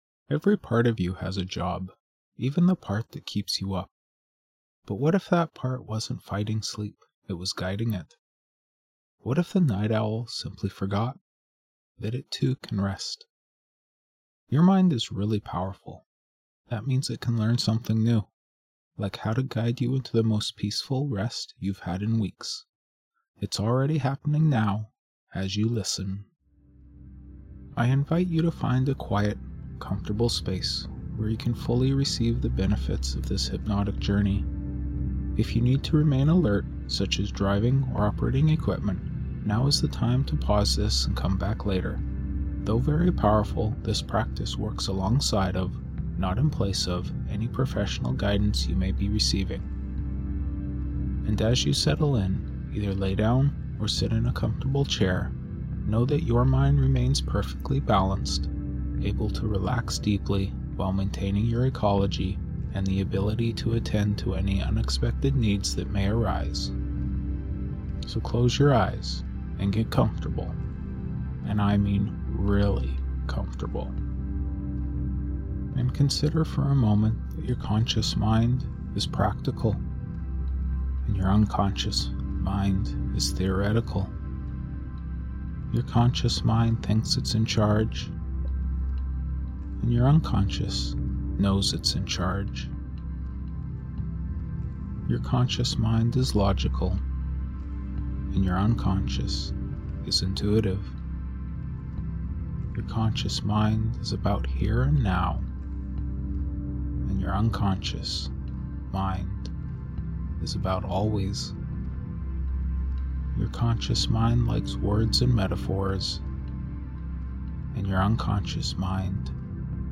The Night Owl is a powerful hypnosis journey designed to help you break free from these nighttime habits and discover the deep, restorative sleep your body craves.